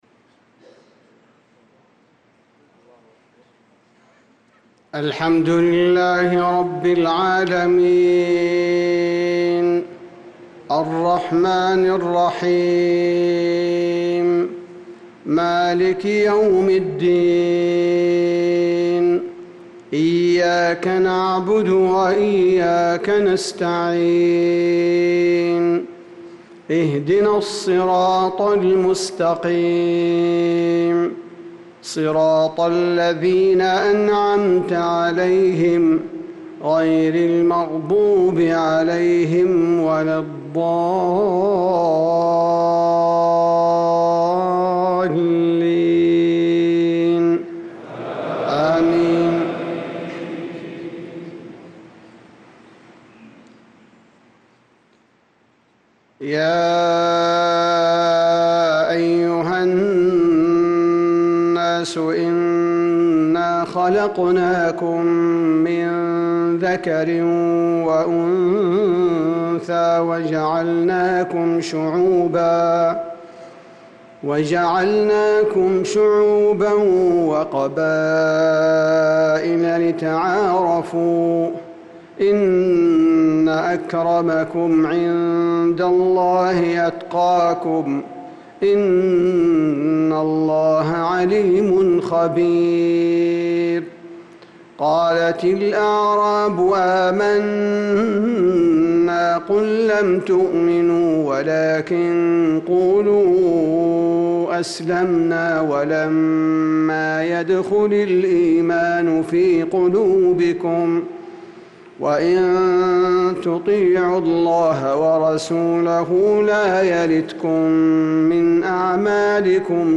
صلاة العشاء للقارئ عبدالباري الثبيتي 11 ذو الحجة 1445 هـ
تِلَاوَات الْحَرَمَيْن .